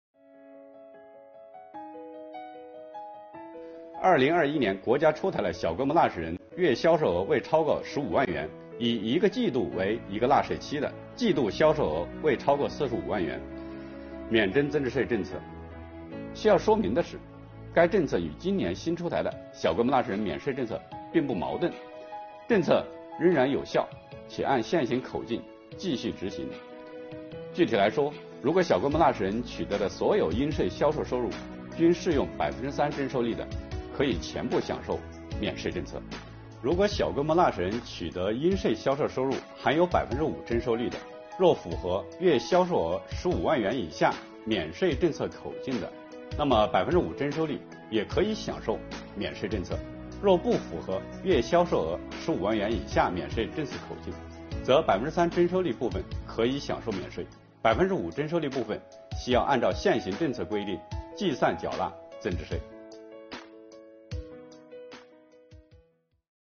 本期课程由国家税务总局货物和劳务税司副司长刘运毛担任主讲人，对小规模纳税人免征增值税政策进行详细讲解，方便广大纳税人进一步了解掌握相关政策和管理服务措施。